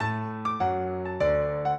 piano
minuet0-2.wav